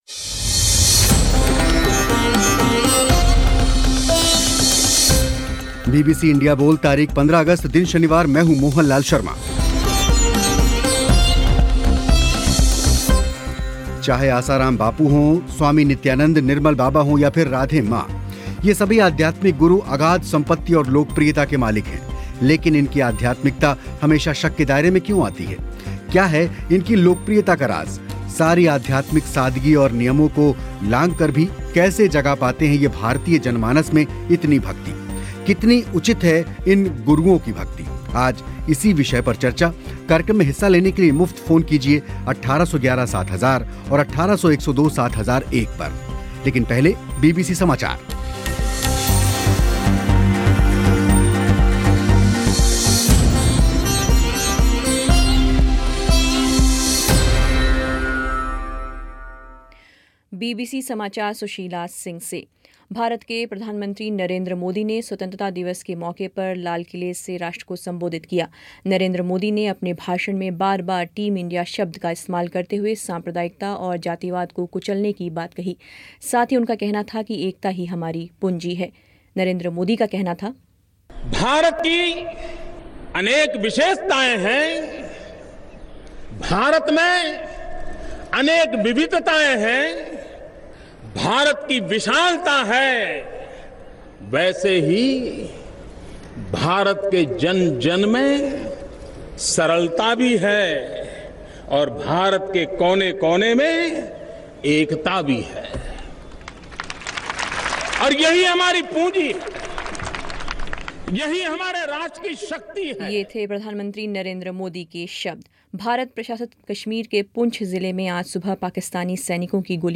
ख़बरें